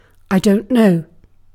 Ääntäminen
Synonyymit God knows Ääntäminen UK Haettu sana löytyi näillä lähdekielillä: englanti Käännös Fraasit 1. ma ei tea Määritelmät Fraasit This entry exists in order to provide translations and derivatives.